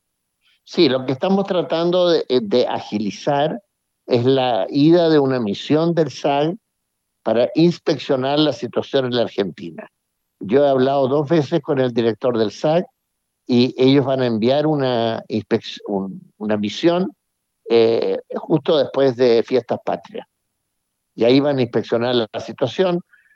En conversación con Radio Bío Bío, el embajador confirmó distintas gestiones que se han estado coordinando luego que se conociera la medida adoptada por el SAG de la suspensión de importación de carne y productos de origen animal desde la Patagonia, al sur del río Colorado.